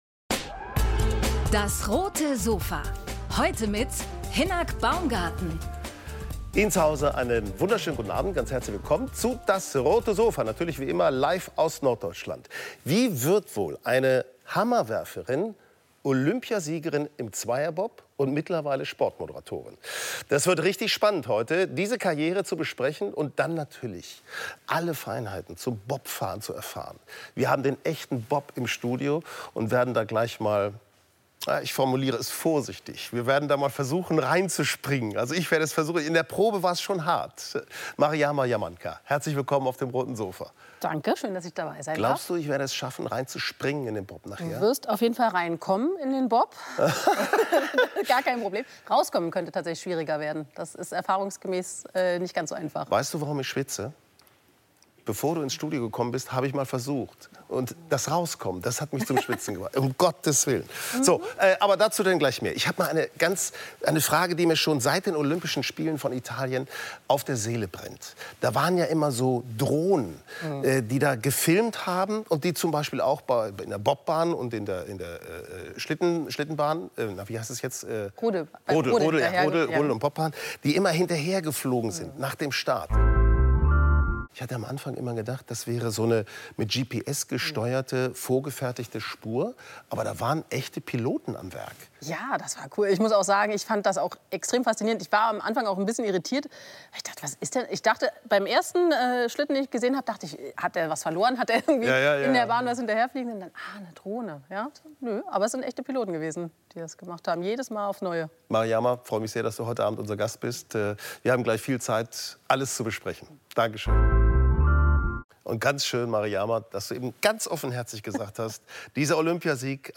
Ex-Bob-Sportlerin und Moderatorin Mariama Jamanka im Sofa-Talk ~ DAS! - täglich ein Interview Podcast